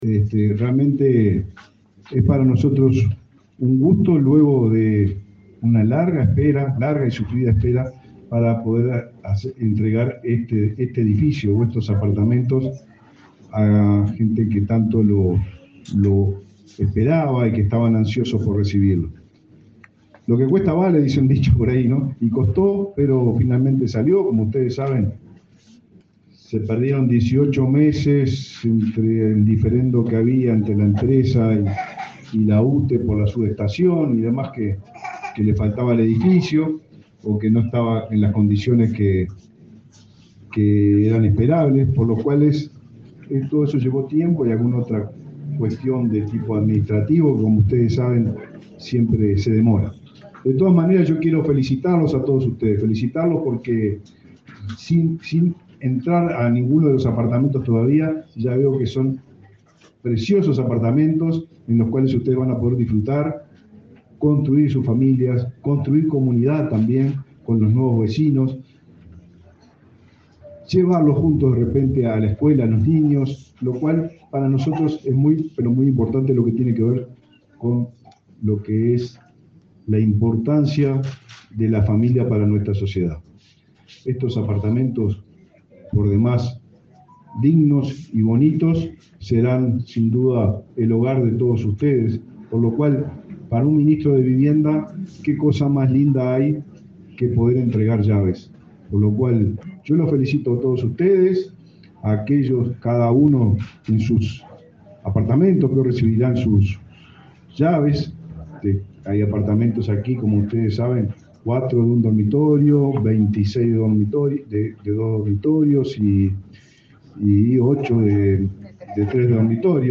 Palabras del ministro de Vivienda, Raúl Lozano
El ministro de Vivienda, Raúl Lozano, participó de la entrega de 38 apartamentos de un edificio en el departamento de San José.